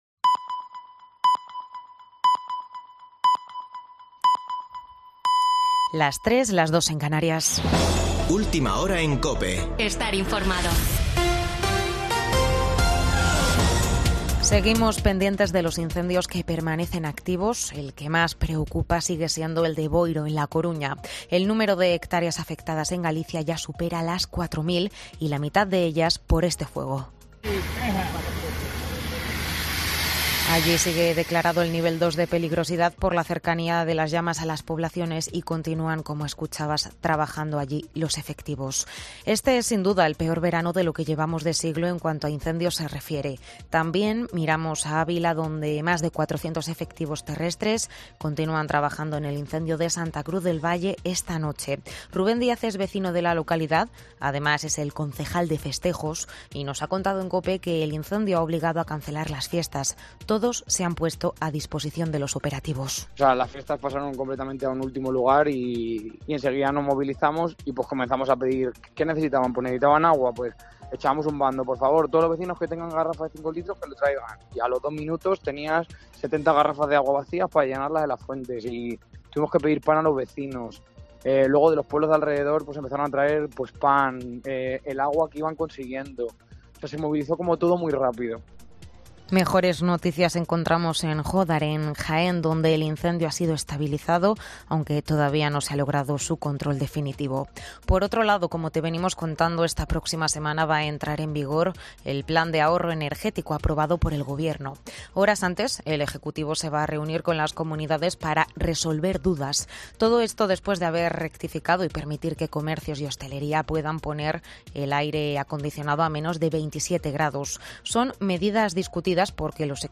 AUDIO: Boletín de noticias de COPE del 7 de agosto de 2022 a las 03.00 horas